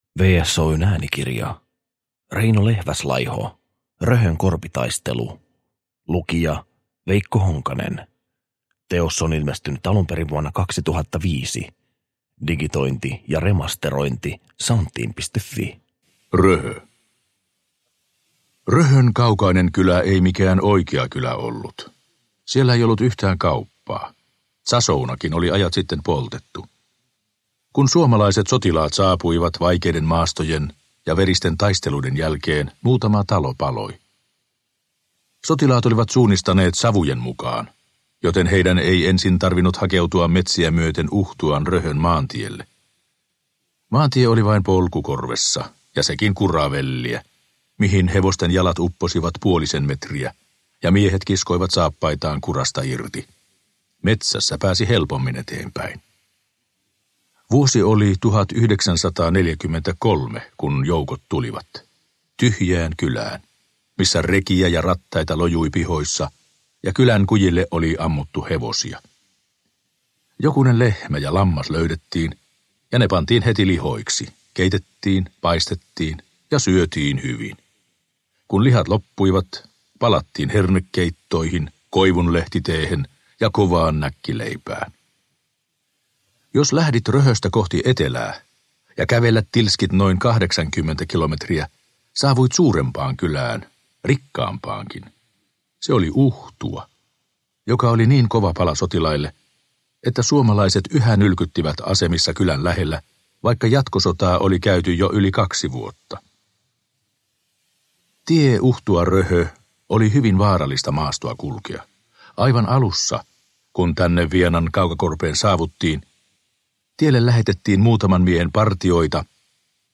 Röhön korpitaistelu – Ljudbok – Laddas ner